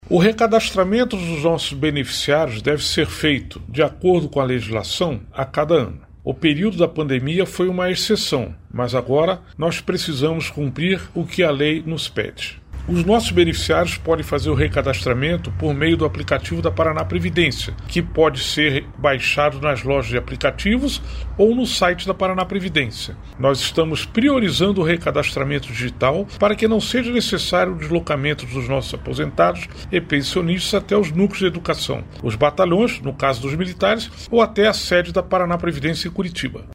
Sonora do diretor-presidente da Paranaprevidência, Felipe Vidigal, alertando que o prazo para recadastramento de aposentados na Paranaprevidência encerra em dezembro